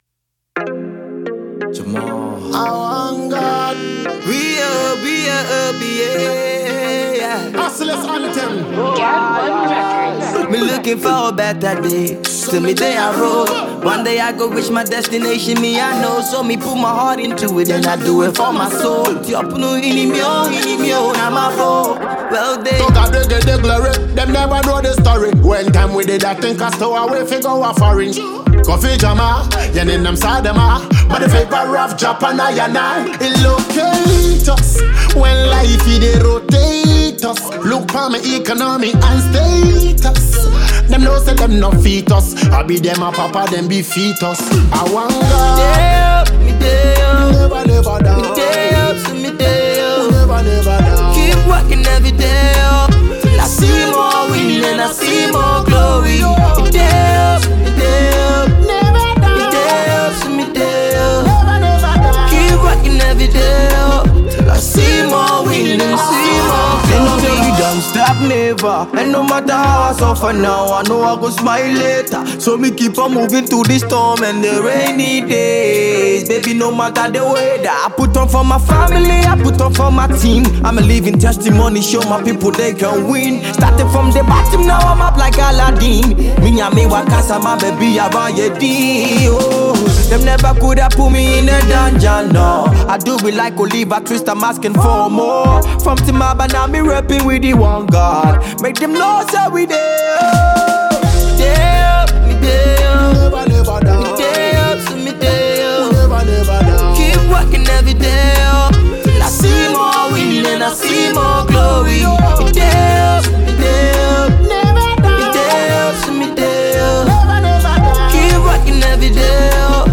Reggae/Dance-hall